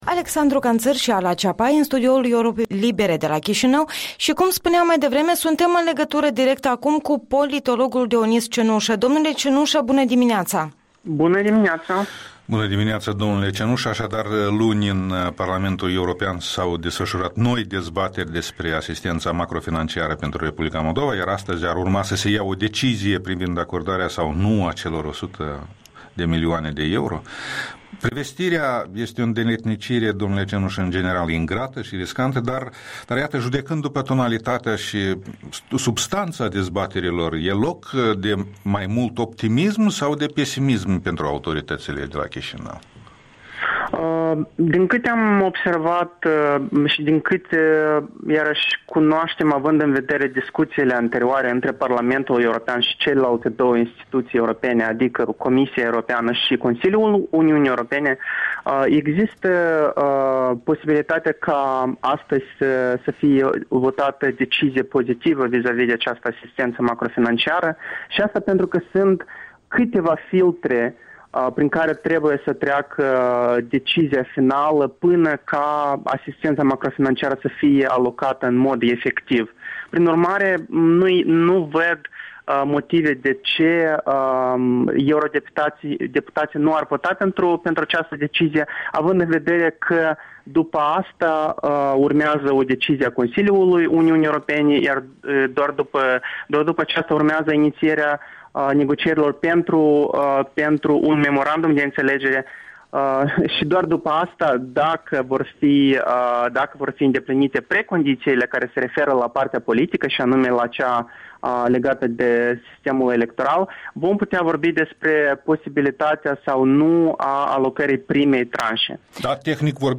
Interviul dimineții și opinii de politolog în ajunul deciziei Parlamentului European asupra ajutorului macrofinanciar pentru Moldova.